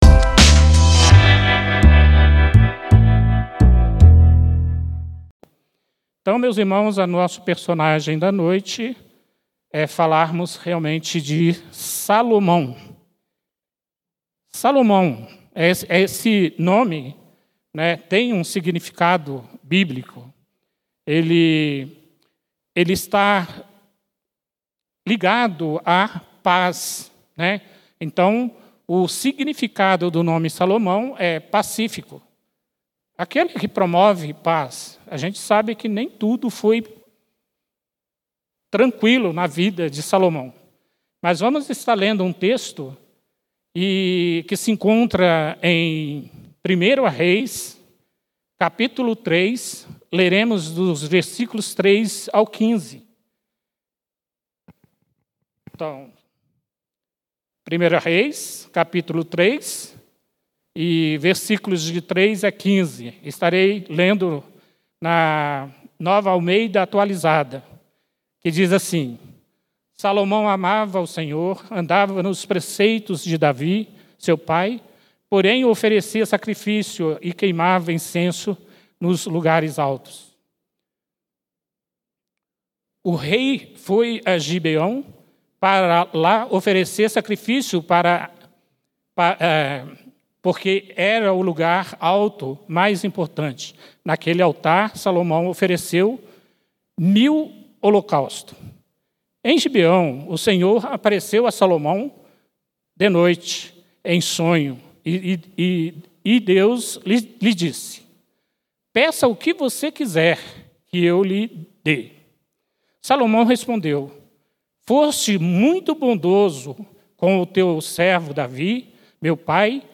Mensagem realizado nos encontros de Reflexão de Oração às Quintas-Feiras 20h.